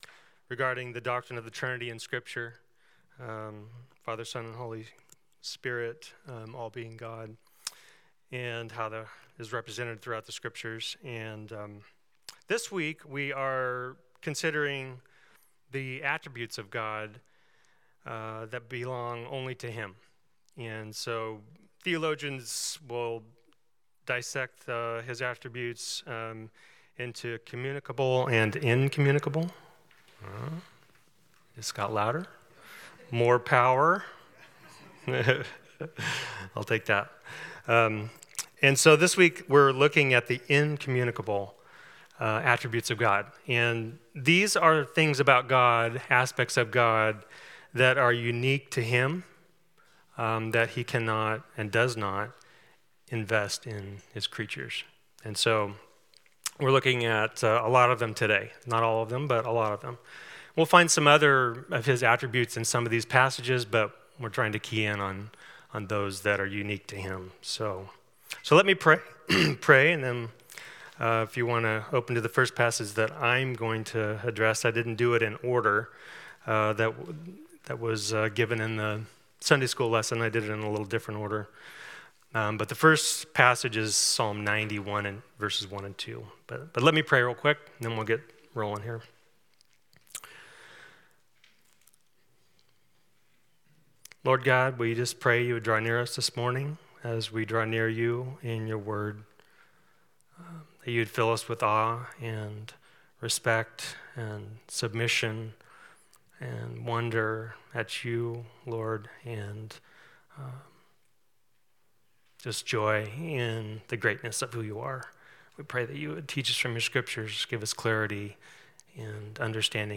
God Is and He Has Spoken Passage: 2 Kings 6:8-23; Psalm 139, 90:1-2; 102:26-28; Isa 40:9-31; Mal 3:6; Heb 13:8 Service Type: Sunday School